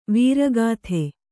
♪ vīra gāthe